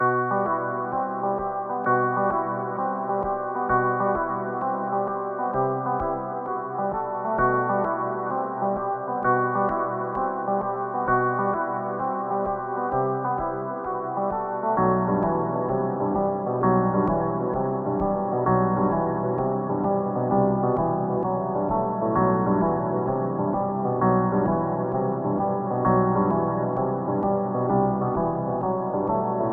描述：铃音表
标签： 130 bpm Trap Loops Bells Loops 4.97 MB wav Key : E FL Studio
声道立体声